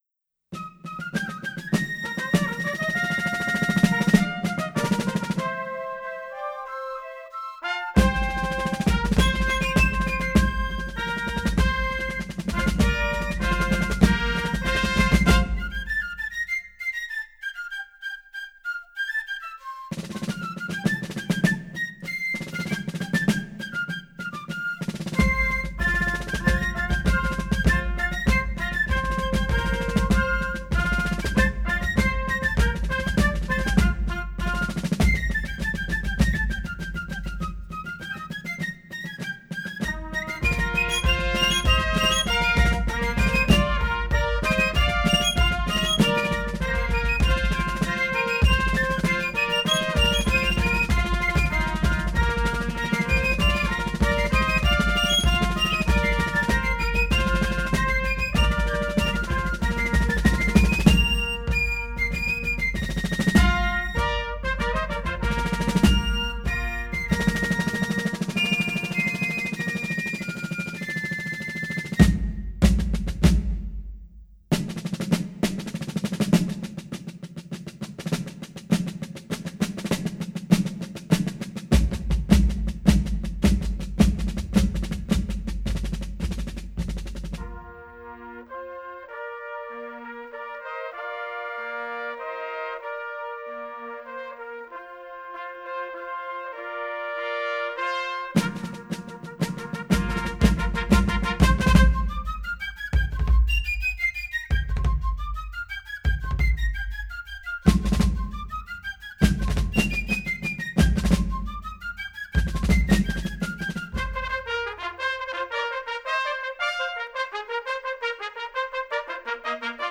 FifeAndDrums.wav